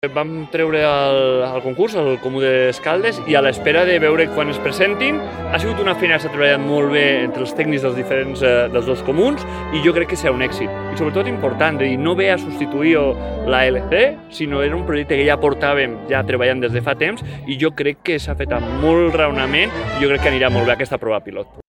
Gonzàlez ha fet aquestes declaracions en el marc de la festa major de Santa Coloma.